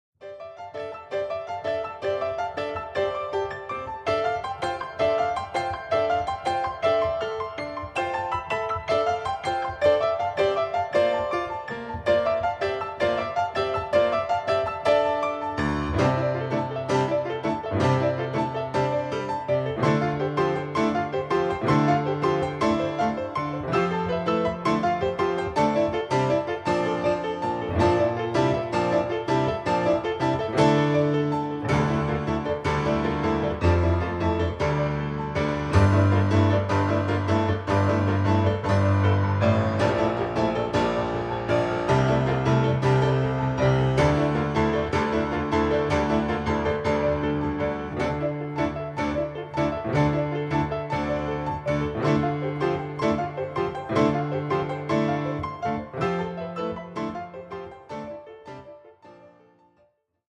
CONTEMPORARY